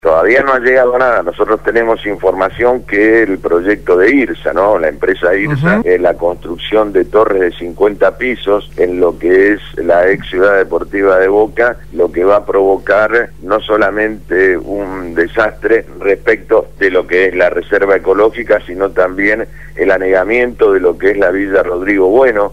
Francisco «Tito» Nenna, Legislador Porteño por el Frente Para la Victoria, habló en el programa Punto de Partida (Lunes a viernes de 7 a 9 de la mañana) de Radio Gráfica FM 89.3 sobre la decisión de la Legislatura de endeudar -a pedido del Jefe de Gobierno- a la Ciudad por 500 millones de dólares.